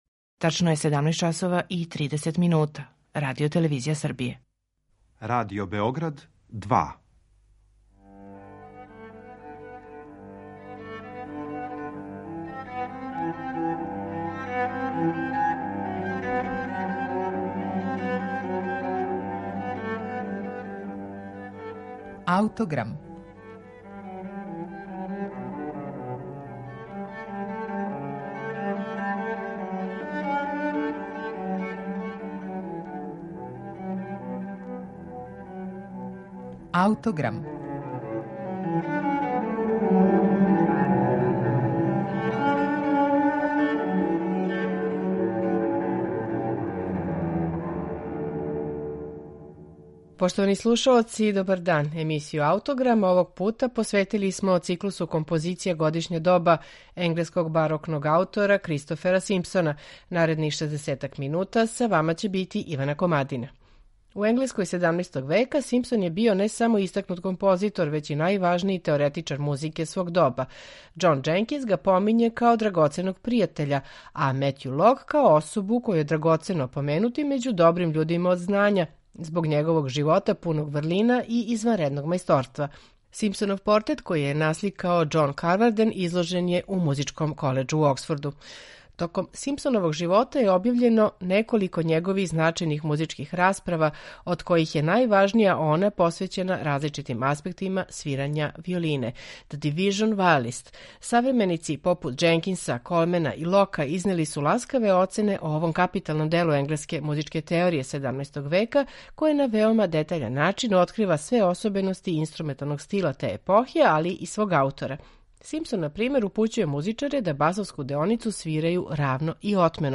Циклус композиција за анасамбл виола 'Годишња доба' Кристофера Симпсона није само врхунац опуса овог аутора, већ и једно од последњих великих дела енглеског музичког стила XVI и XVI века.